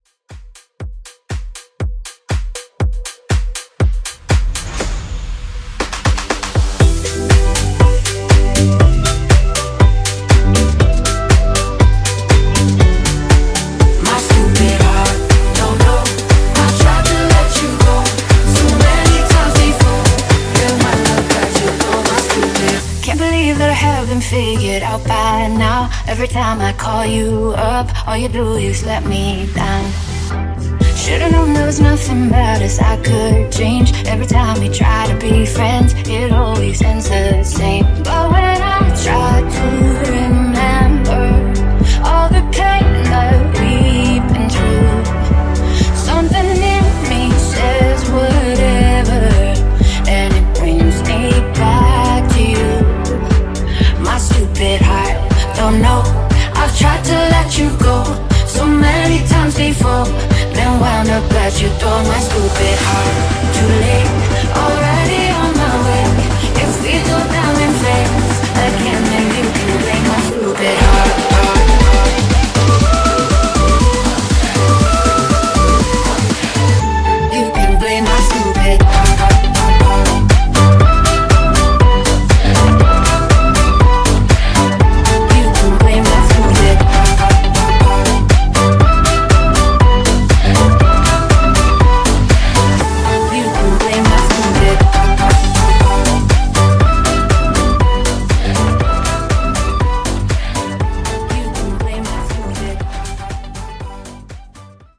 Genre: DANCE Version: Clean BPM: 120 Time